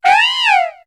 Cri de Léopardus dans Pokémon HOME.